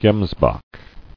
[gems·bok]